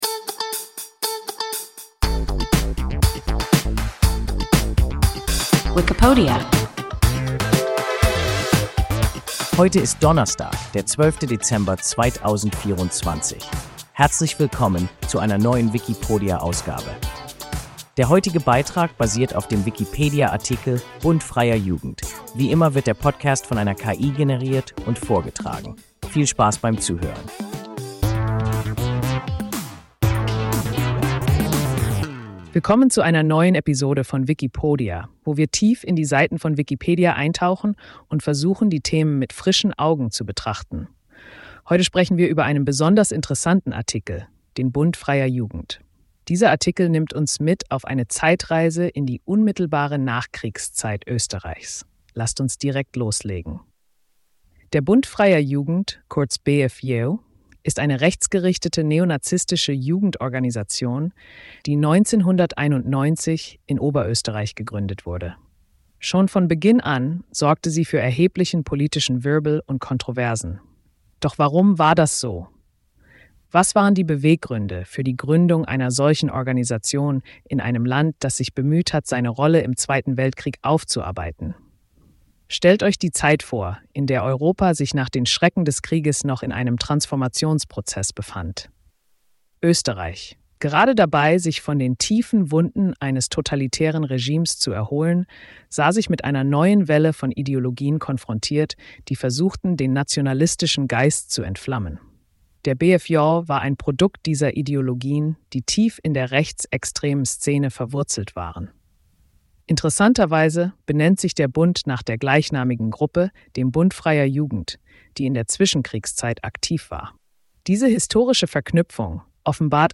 Bund freier Jugend – WIKIPODIA – ein KI Podcast